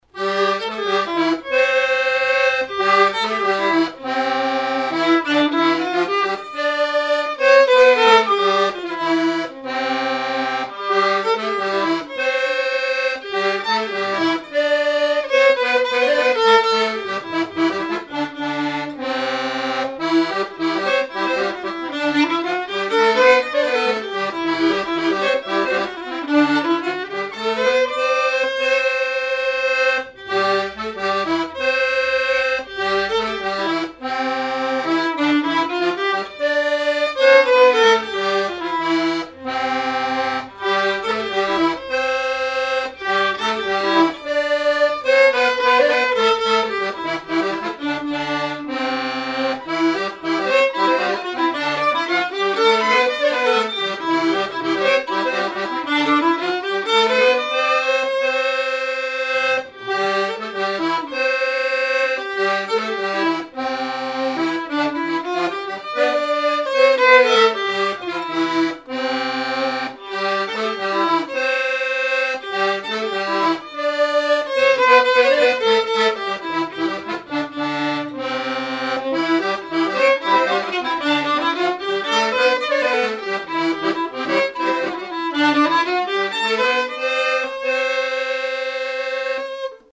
mené comme marche nuptiale
Mouchamps
danse : quadrille : avant-quatre
Pièce musicale inédite